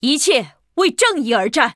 文件 文件历史 文件用途 全域文件用途 Lobato_amb_01.ogg （Ogg Vorbis声音文件，长度1.7秒，108 kbps，文件大小：23 KB） 文件说明 源地址:游戏语音 文件历史 点击某个日期/时间查看对应时刻的文件。 日期/时间 缩略图 大小 用户 备注 当前 2018年11月17日 (六) 03:33 1.7秒 （23 KB） 地下城与勇士  （ 留言 | 贡献 ） 分类:洛巴赫 分类:地下城与勇士 源地址:游戏语音 您不可以覆盖此文件。